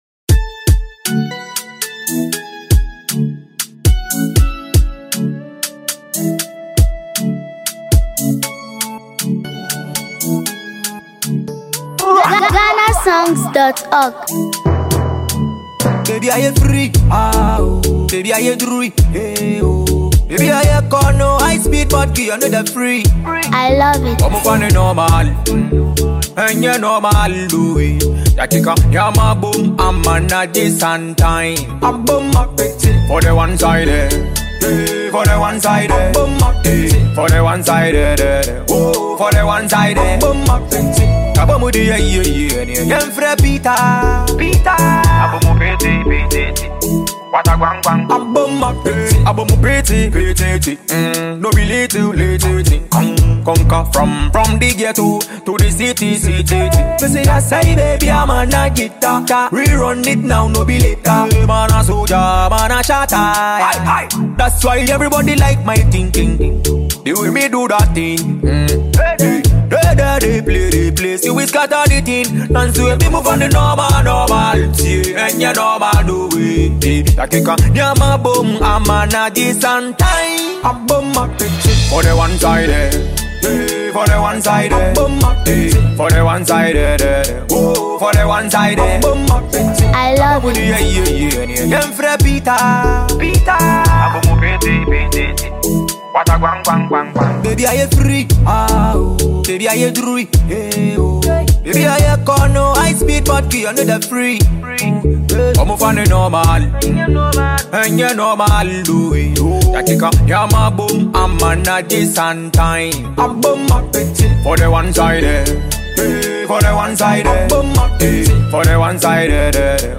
Ghanaian multi-talented dancehall, afrobeats
energetic and entertaining record
and catchy melodies
and music fans who enjoy groovy African rhythms.